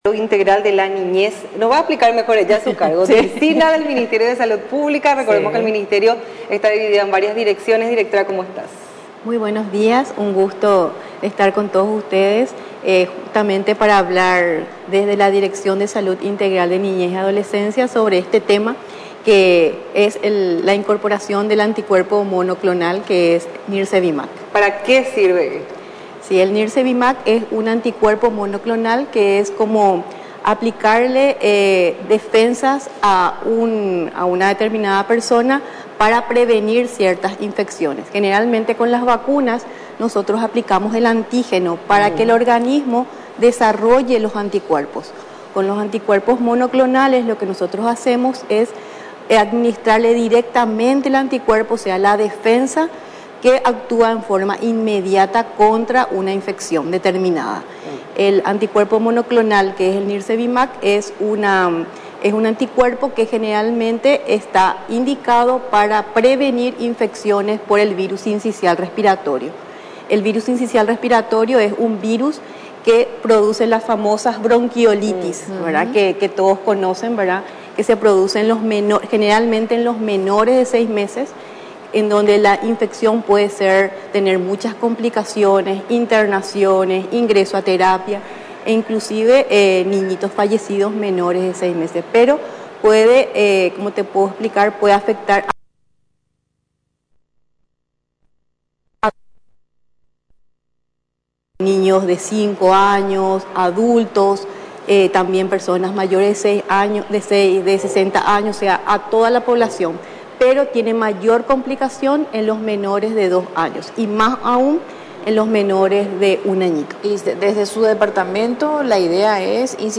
Durante la entrevista en Radio Nacional del Paraguay, explicó los detalles sobre lo relacionado al anticuerpo para los lactantes.